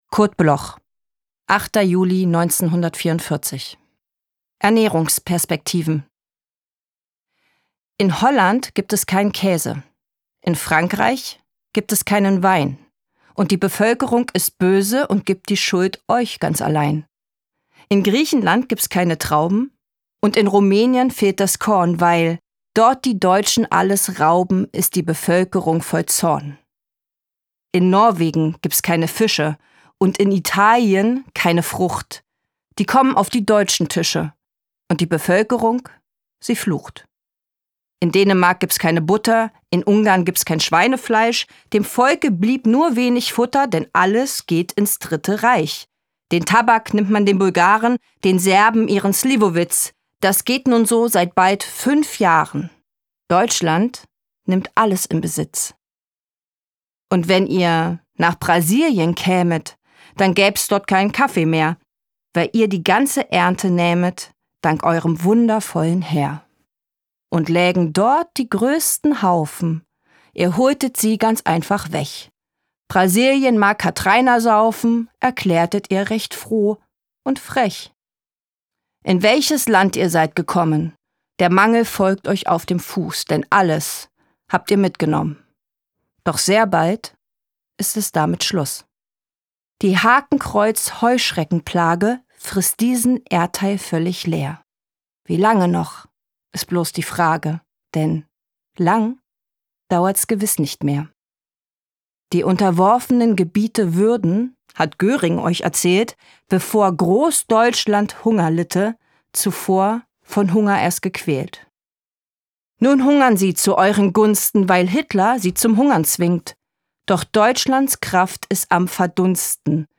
Luise Heyer (*1985) is een Duitse actrice.
Recording: Speak Low, Berlin · Editing: Kristen & Schmidt, Wiesbaden